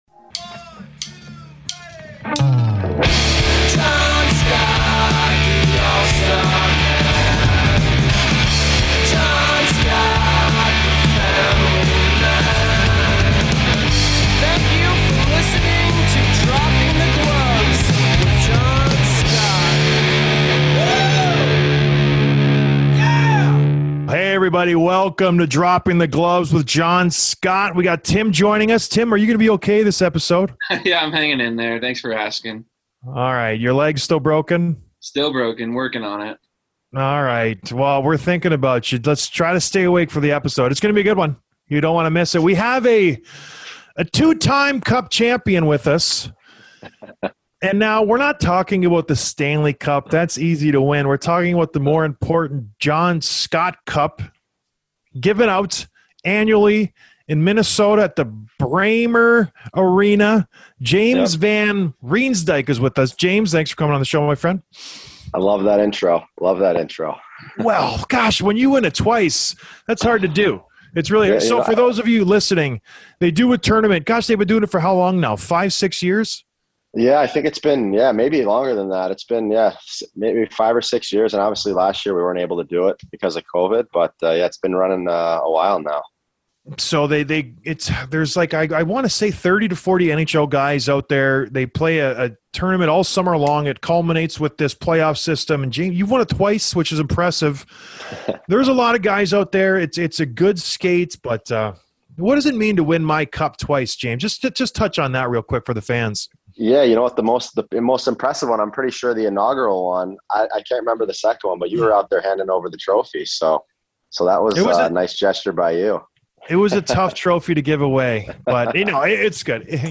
Interview with James van Riemsdyk, Philadelphia Flyers